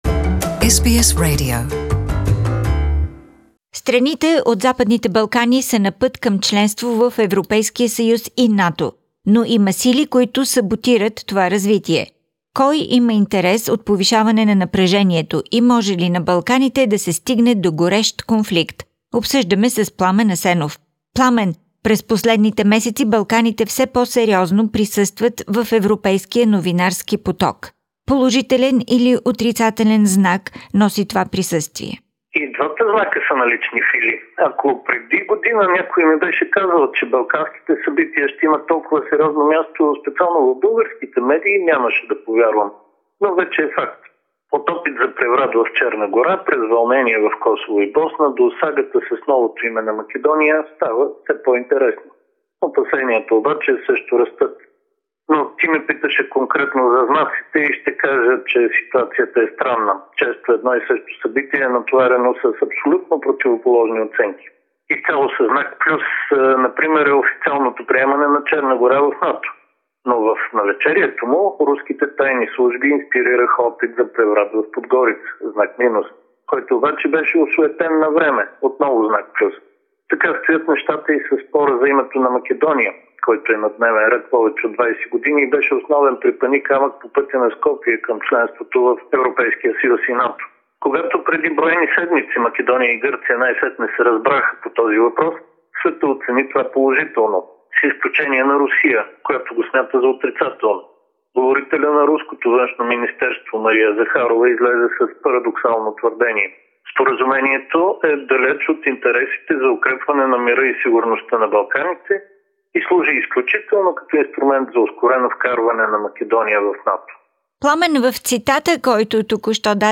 Political Analysis